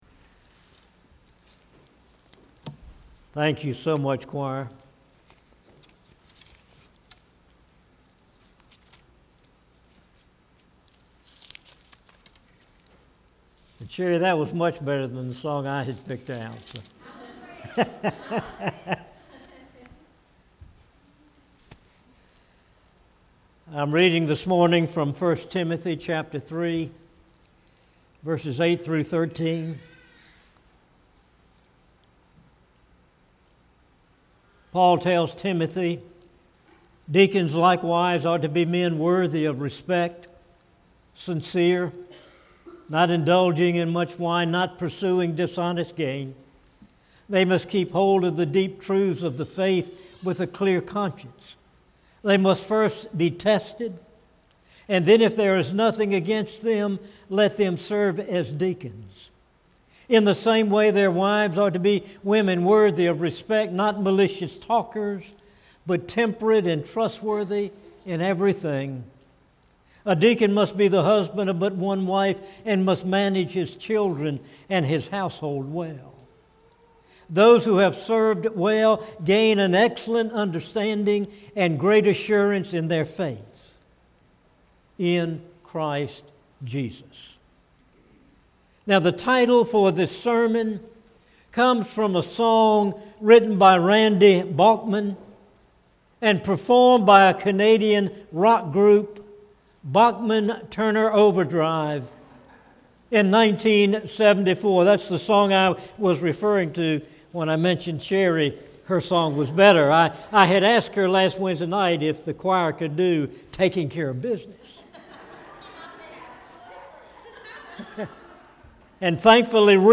Tramway Baptist Church Sermons